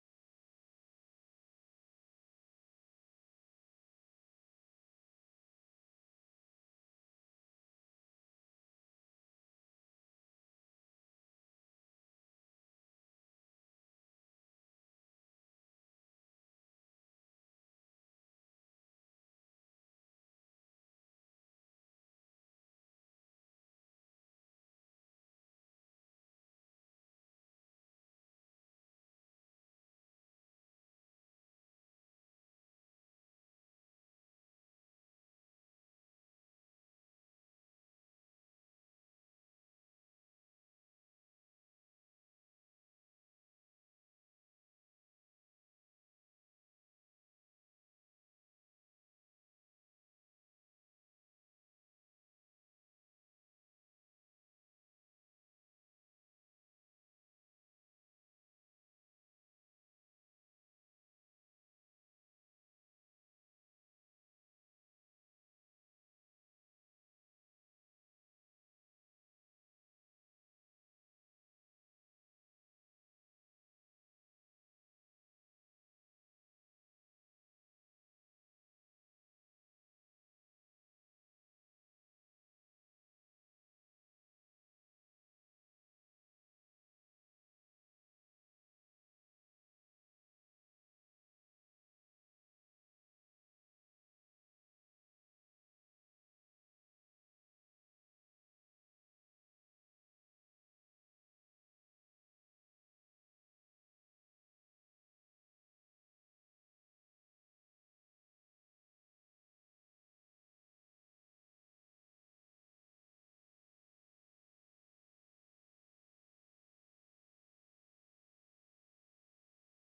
Our worship team leads us in some amazing worship.